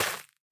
Minecraft Version Minecraft Version latest Latest Release | Latest Snapshot latest / assets / minecraft / sounds / block / rooted_dirt / break1.ogg Compare With Compare With Latest Release | Latest Snapshot